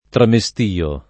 tramestio [ trame S t & o ]